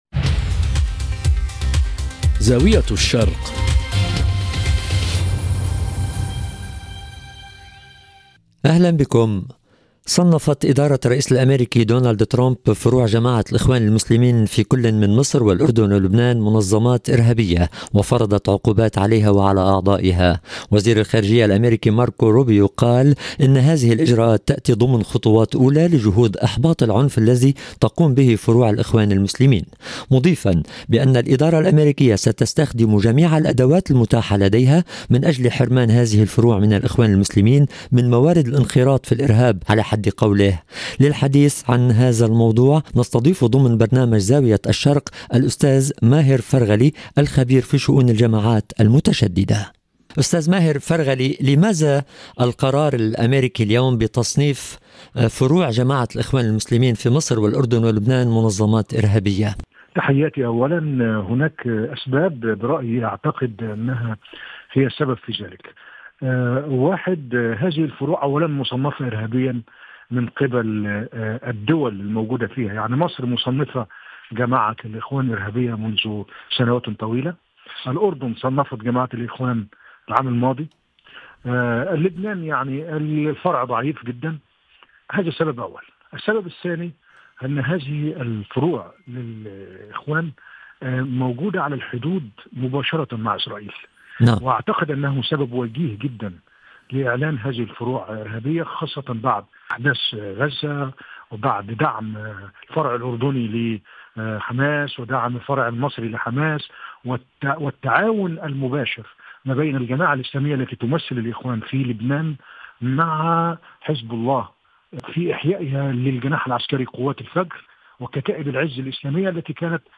الحوار كاملاً